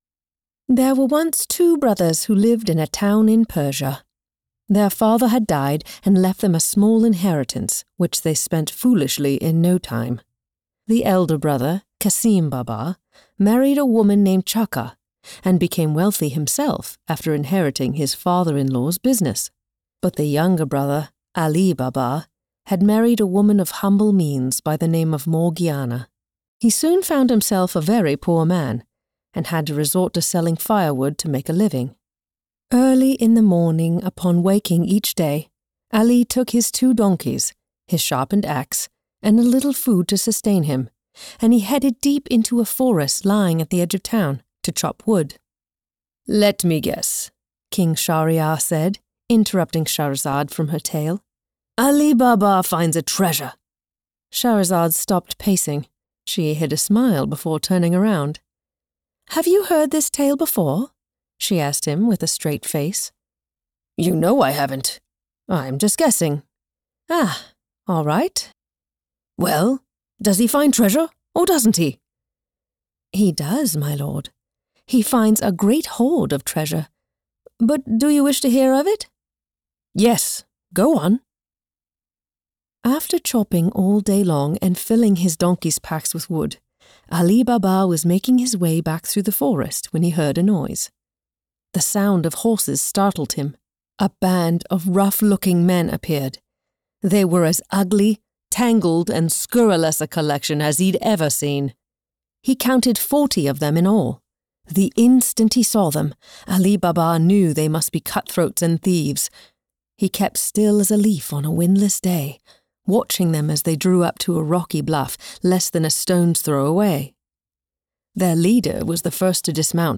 Warm, flexible, seasoned, and authentic.
Fiction w Accent (British General)